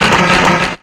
Cri de Solaroc dans Pokémon X et Y.